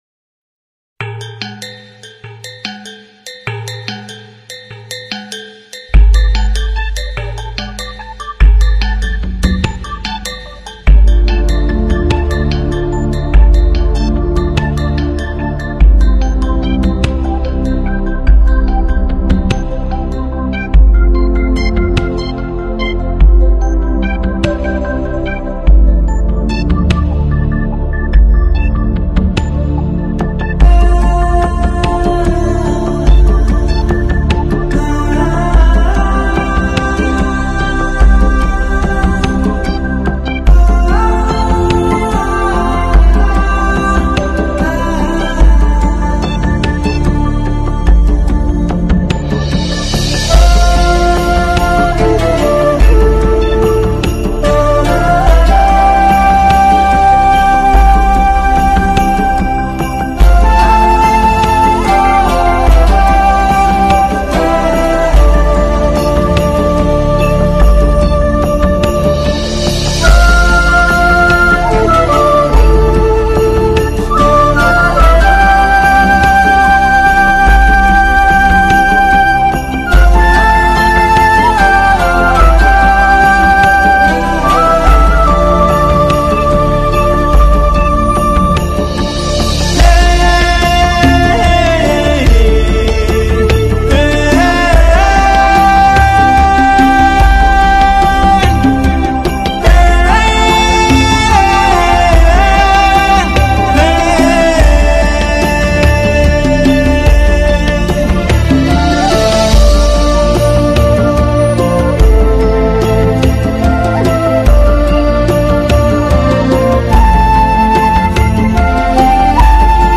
本专辑是印第安音乐精选辑，非常动听的排箫，伴以其他乐器，
原住民的吟唱，土著之长笛所构成的美洲音乐，是那样的哀婉淳朴。